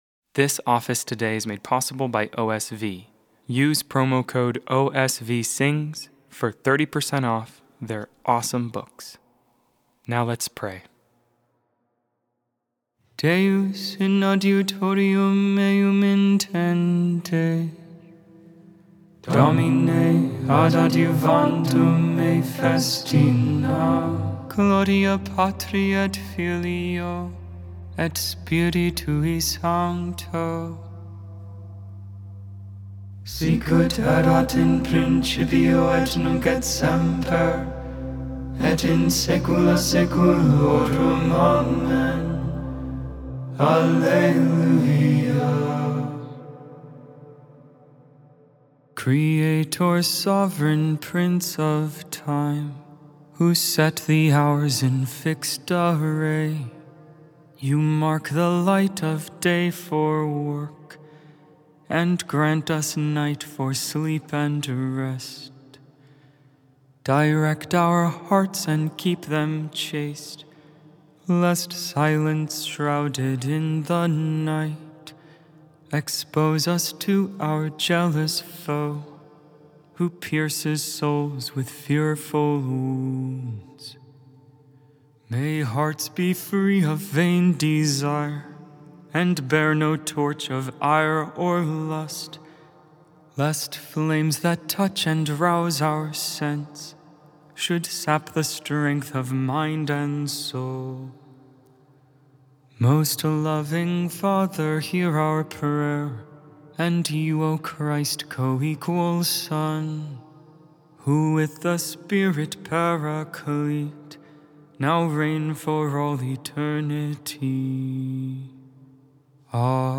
3.4.25 Vespers, Tuesday Evening Prayer of the Liturgy of the Hours